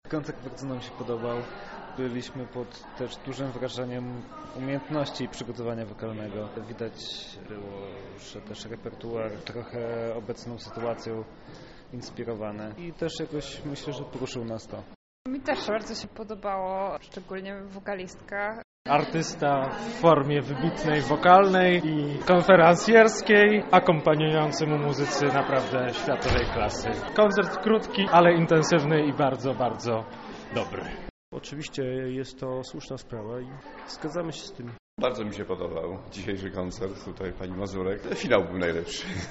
Z uczestnikami rozmawiał nasz reporter: